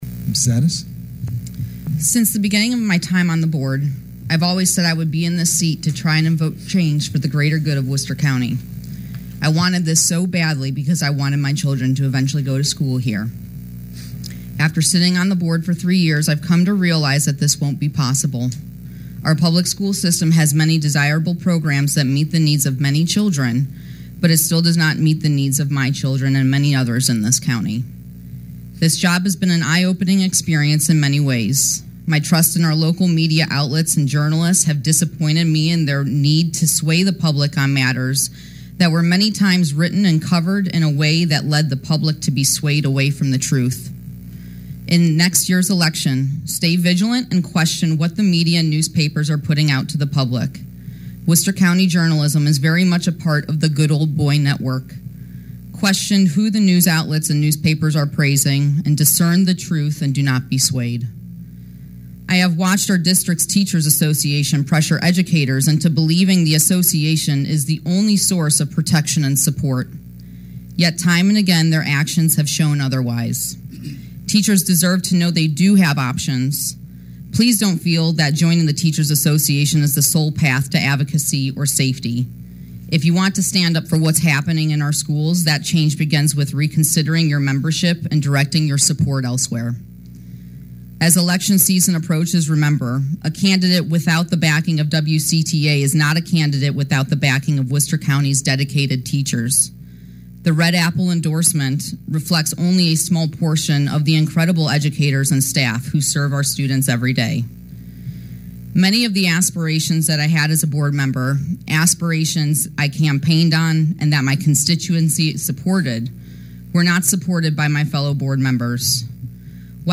However Addis spoke to the board for just over six minutes and explained her reasons for resigning in full.  She spoke of a lack of trust in the local media to report on the issues – rather than be swayed by the ‘good ole boy’ network, a teachers’ association that bullies non-members, lack of support on aspirations that she campaigned on for her constituents, the destructive culture of the school system and budget over-spending while giving county residents the belief that the district budget is not fully funded.
ORIGINAL STORY – 12/03/25 – At the Worcester County Board of Education’s annual public budget input meeting yesterday, Katie Addis, elected Board of Education member representing district six, resigned from her position, effective immediately.